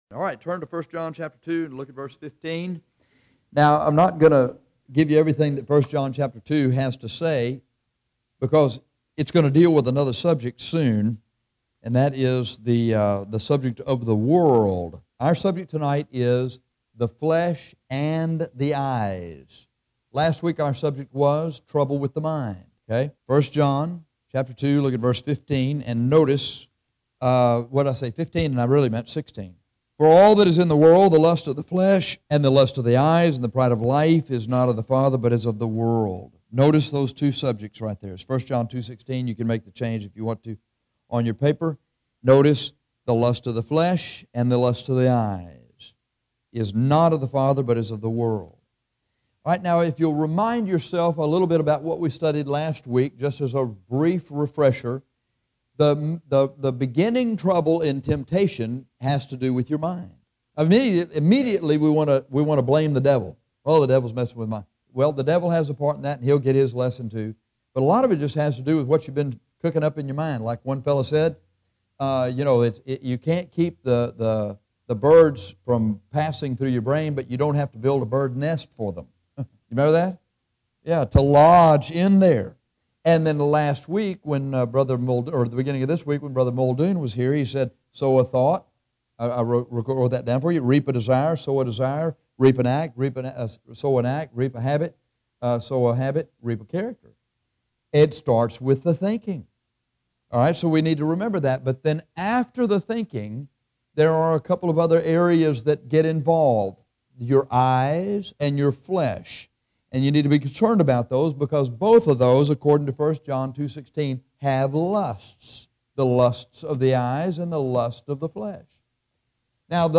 In this series on dealing with temptation, we see several problem areas in our lives. This lesson deals with the lust of the flesh and eyes.